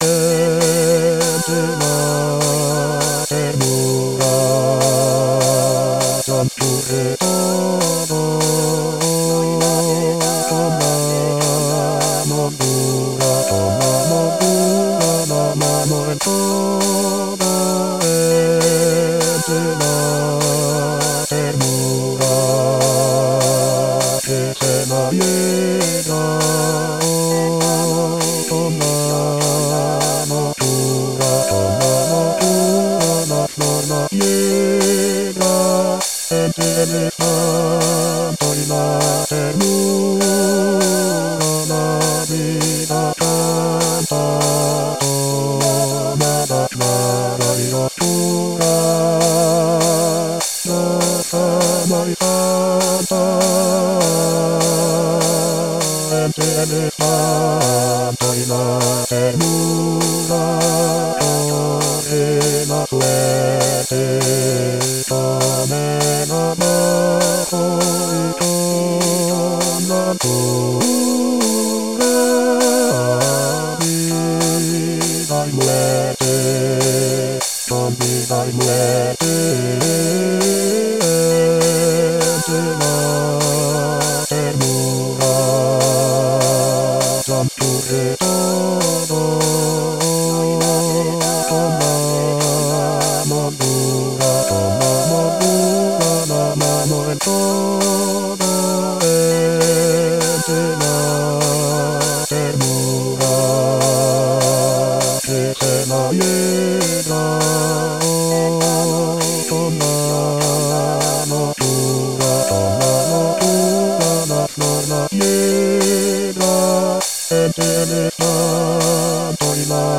Bass Bass 2